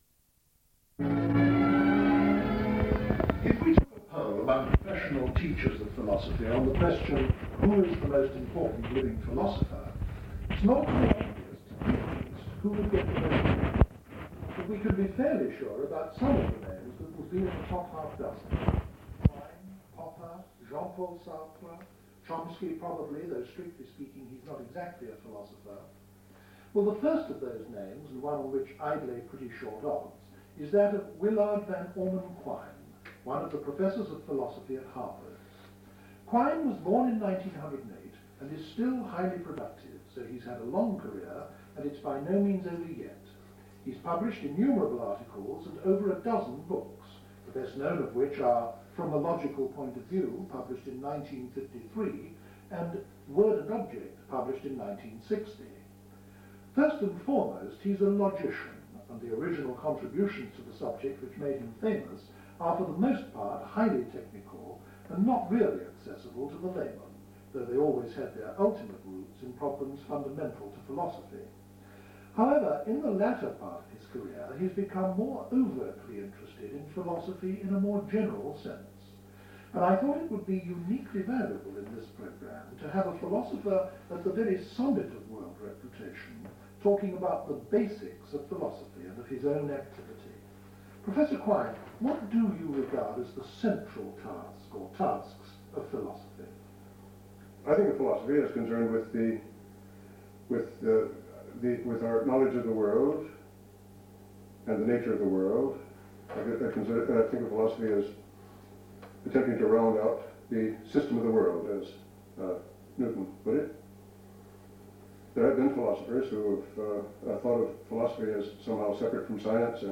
16/03/78 Television programme incl Quine & Magee - Archive Trust for Research in Mathematical Sciences & Philosophy